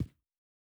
Shoe Step Stone Medium D.wav